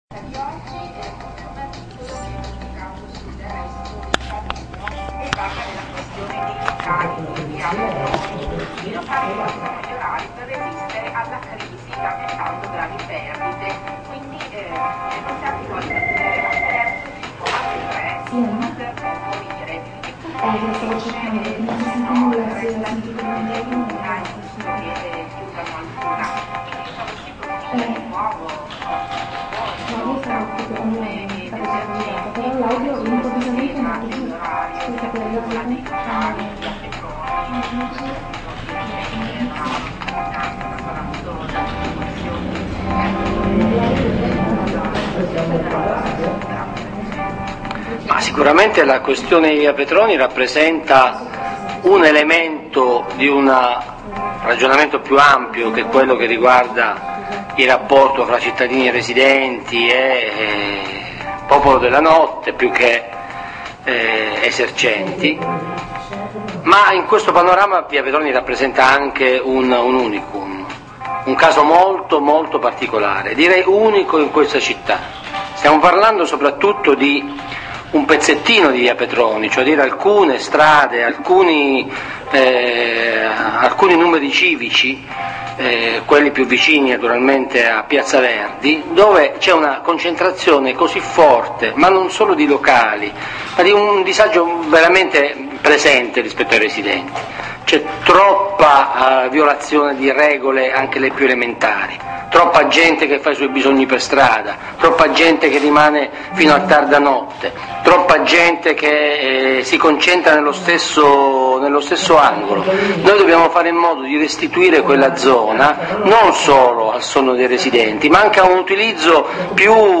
Intervista su AlmaRadio del capogruppo PD Sergio Lo Giudice 20 gennaio 2012
Ascolta l'intervista effettuata durante la trasmissione Superpartes, il programma di AlmaRadio, la radio degli studenti di Bologna e provincia, in cui facciamo il punto sull'attualità e soprattutto sul prossimo bilancio del Comune. Venerdì 20 gennaio 2012 è stato ospite il capogruppo PD Sergio Lo Giudice.